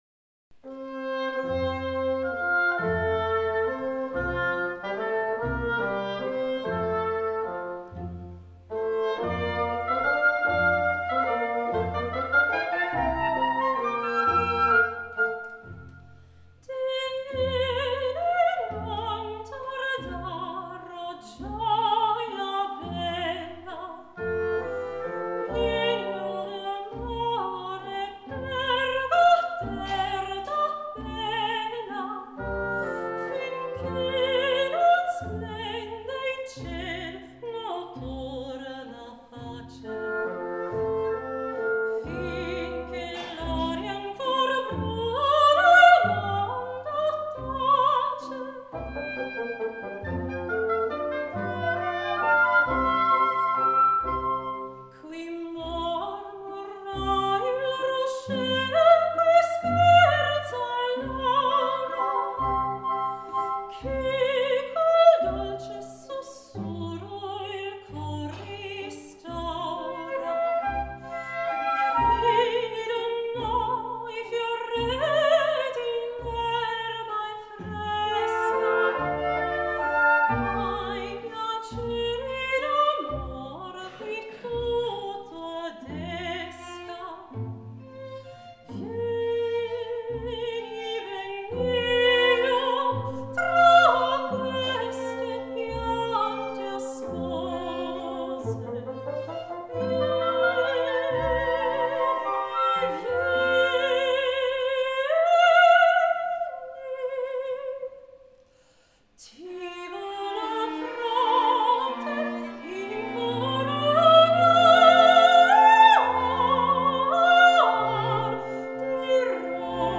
Capella Salonisti (Live Aufnahmen)
Sopran